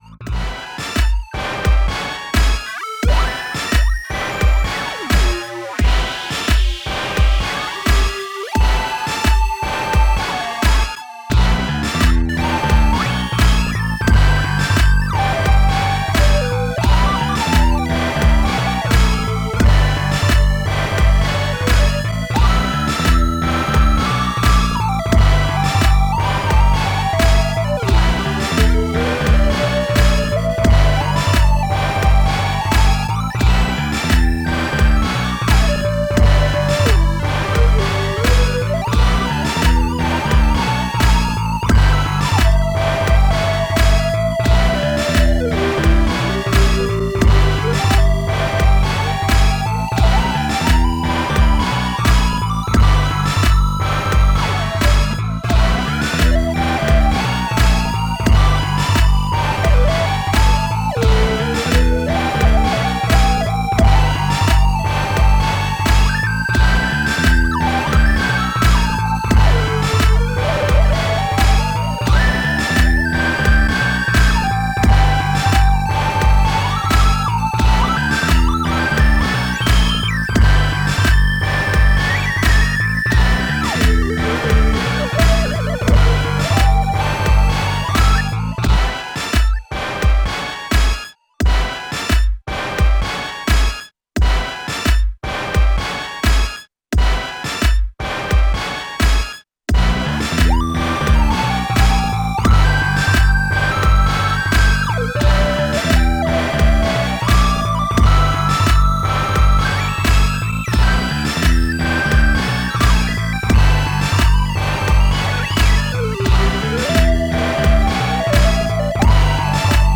Kaossilator with Looping Synth, epic.